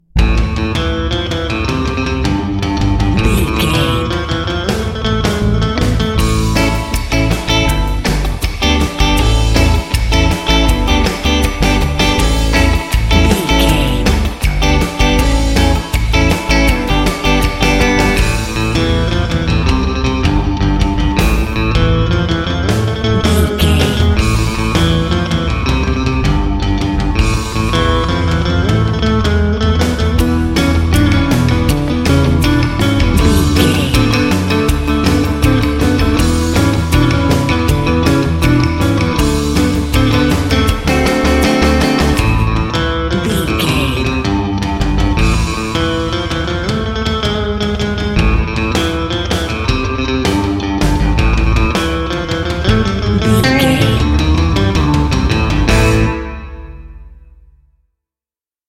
Fast paced
Aeolian/Minor
groovy
driving
energetic
funky
electric guitar
drums
bass guitar